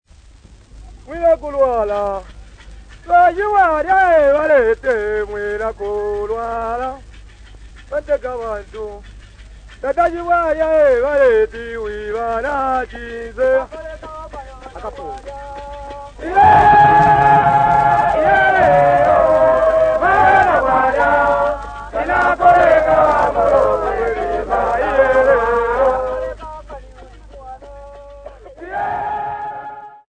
Bemba Singers
Popular music--Africa
field recordings
sound recording-musical
Drinking song for Mupukumo dance with Bemba men and women with Muwaya rattle accompaniment